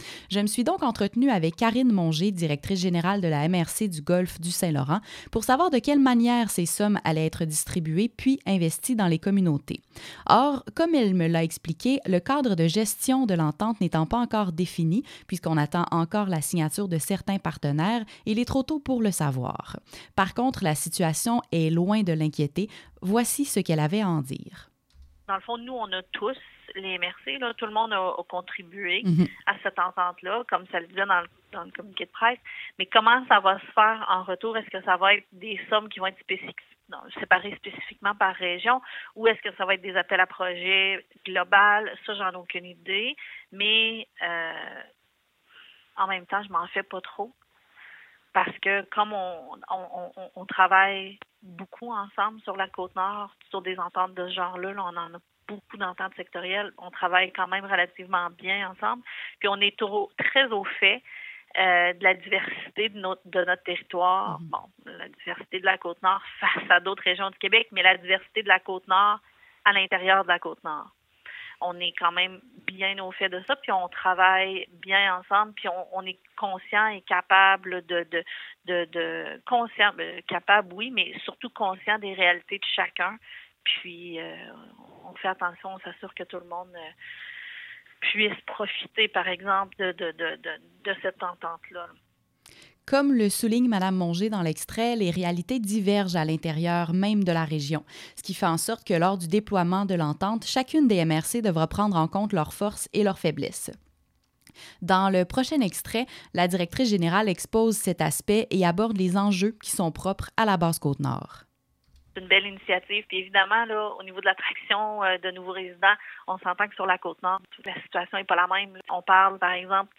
MRC-segment-radio.mp3